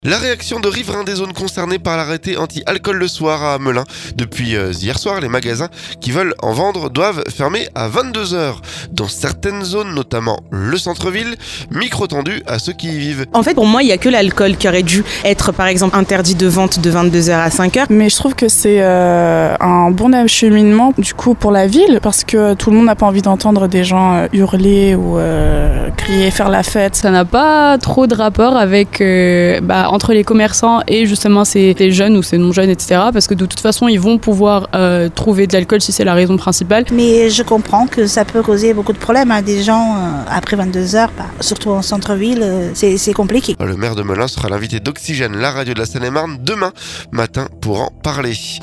La réaction de riverains des zones concernées par l'arrêté anti alcool le soir à Melun. Depuis mardi soir, les magasins qui en vendent doivent fermer à 22H dans certaines zones, notamment du centre. Micro tendu à ceux qui y vivent.